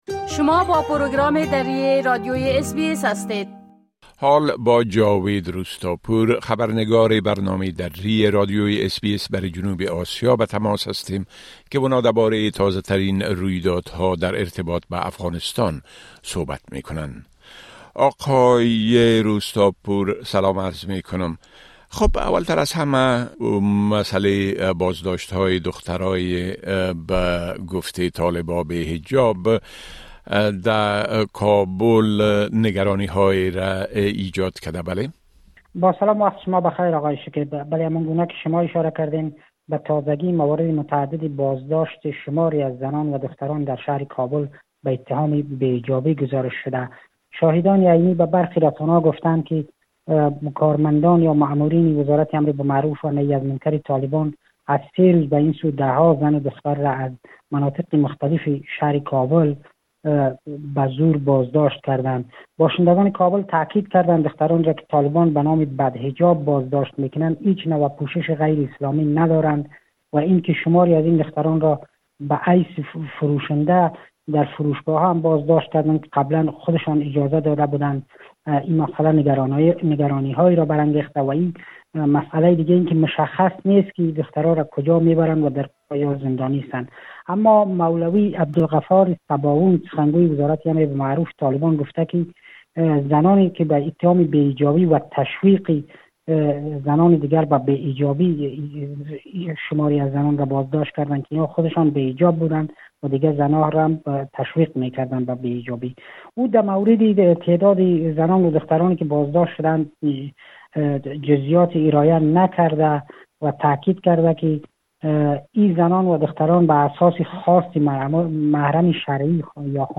گزارش كامل خبرنگار ما، به شمول اوضاع امنيتى و تحولات مهم ديگر در افغانستان را در اينجا شنيده مى توانيد.